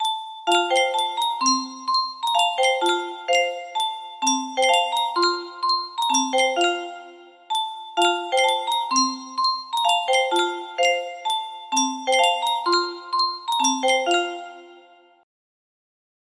Yunsheng Boite a Musique - Ils Ont des Chapeaux Ronds 2553 music box melody
Full range 60